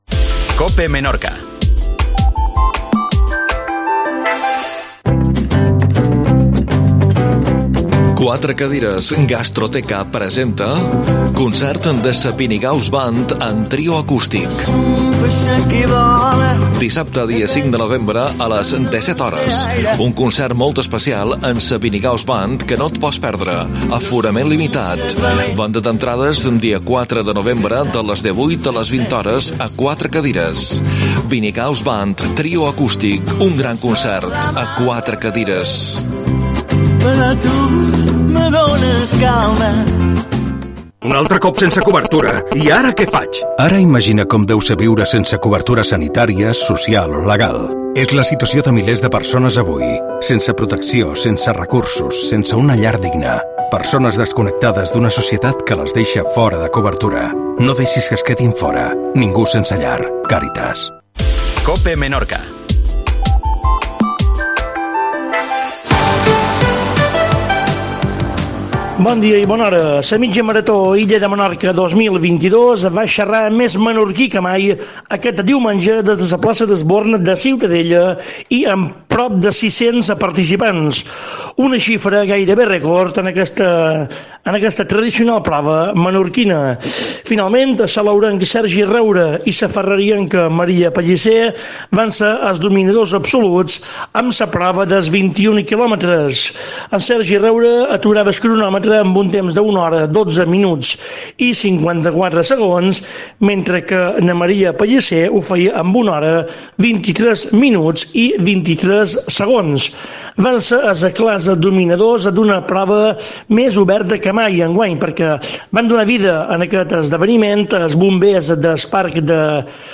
Informacio esportiva